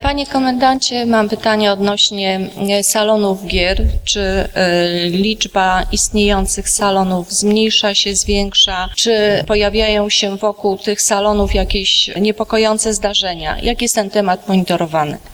Salony gier i ich legalność – to jedno z zagadnień poruszanych na 24. Sesji Rady Miejskiej w Stargardzie.
– dopytywała radna Elżbieta Dybowska.